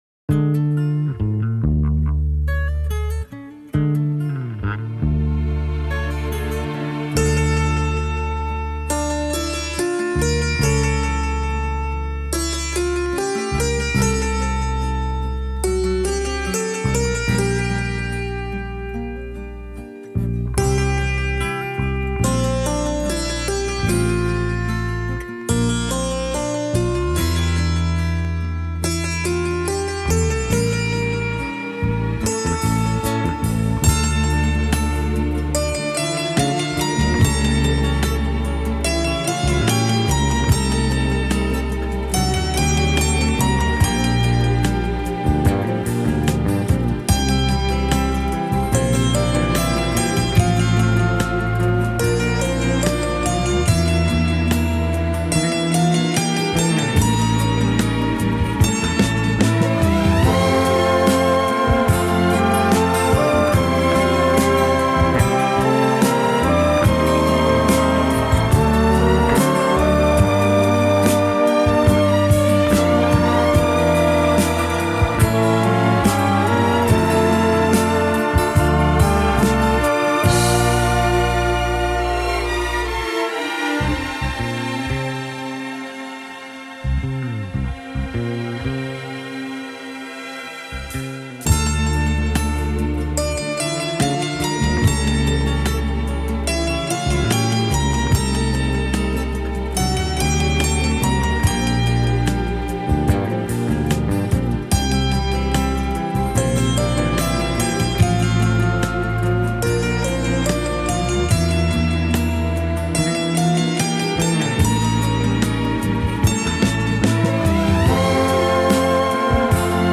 Klaviersolo